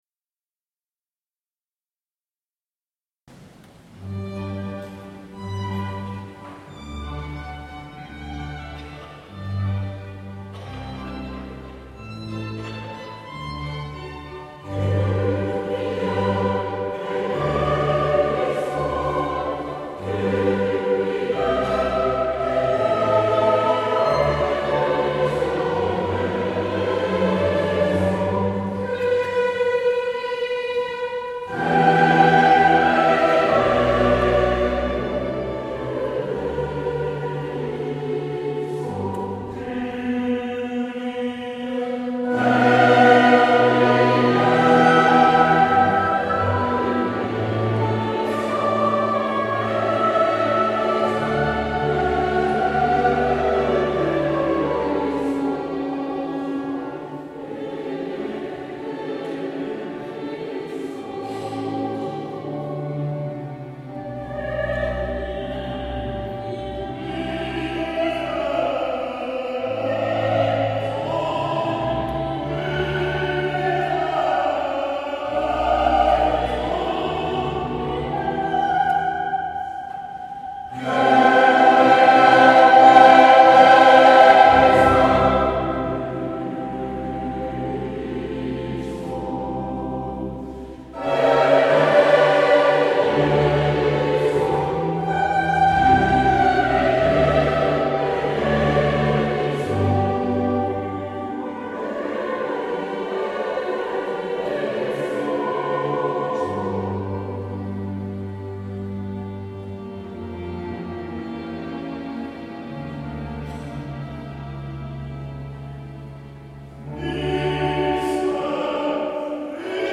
Kyrie_Kirchenchor_St__Karl_mp3